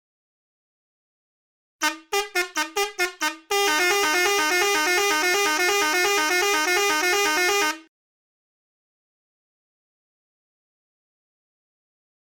The Hi-Do 650mm Chrome Air Horn Pair delivers a deep, commanding tone ideal for trucks, lorries, and commercial vehicles. Precision-engineered in Turkey, these twin marine-style air horns produce an impressive 118dB sound level at 400Hz, ensuring you’re heard in any condition.